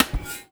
R - Foley 174.wav